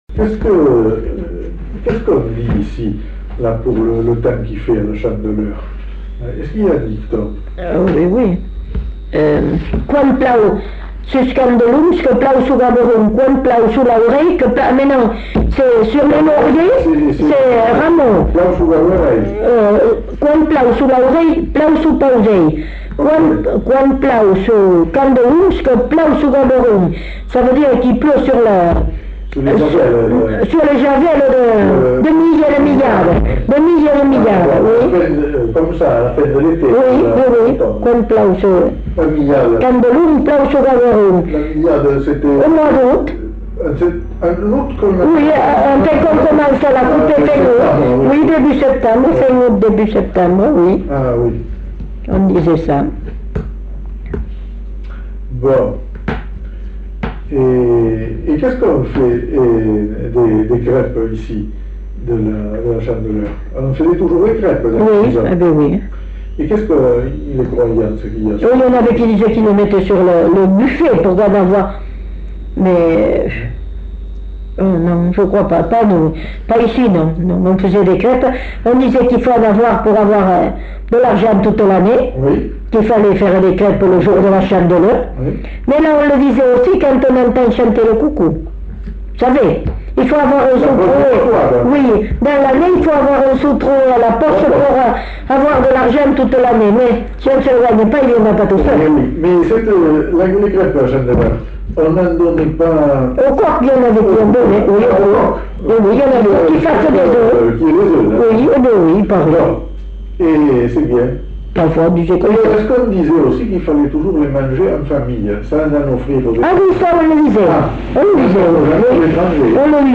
Lieu : Belin-Beliet
Genre : témoignage thématique